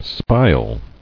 [spile]